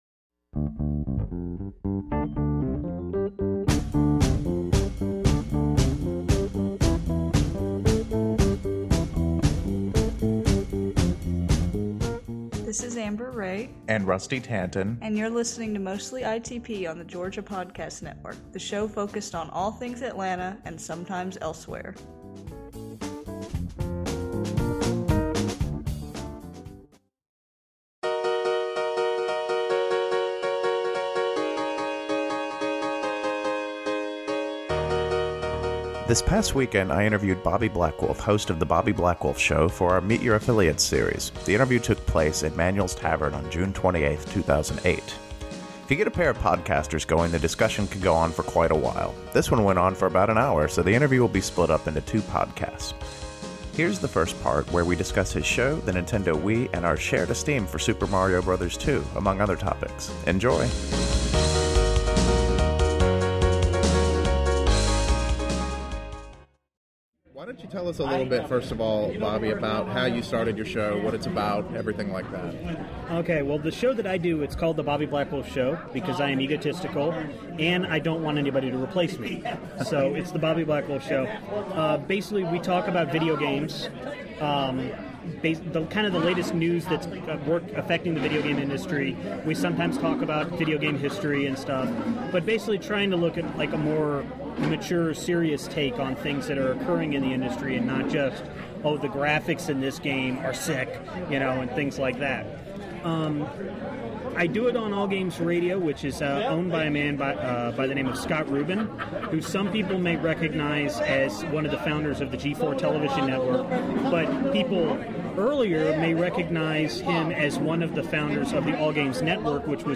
The interview is broken into two parts, of which this is the first.